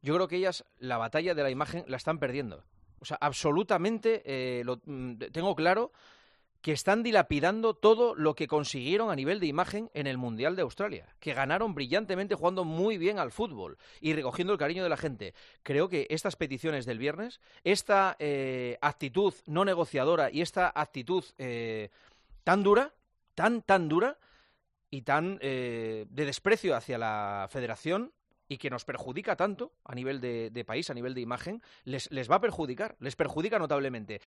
El director de El Partidazo de COPE opina sobre la polémica de las futbolistas de la selección española que se niegan a ser convocadas.